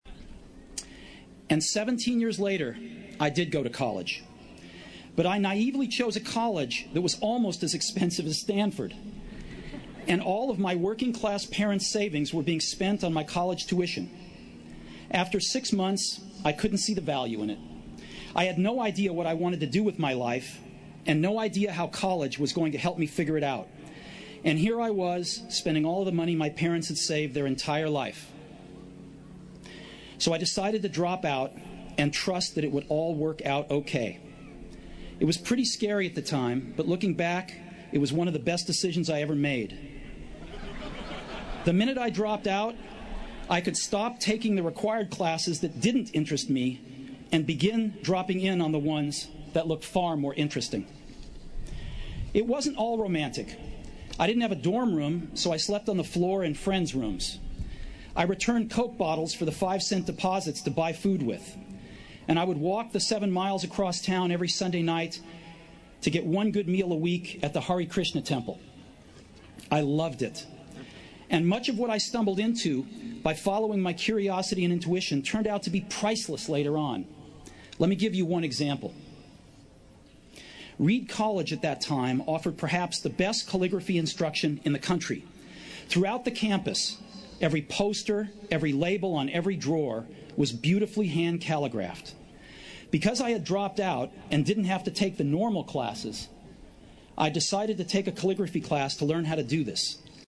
借音频听演讲，感受现场的气氛，聆听名人之声，感悟世界级人物送给大学毕业生的成功忠告。